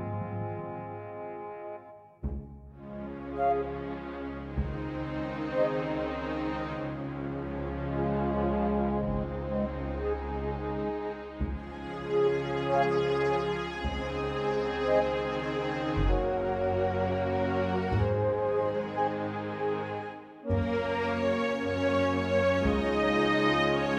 Musicals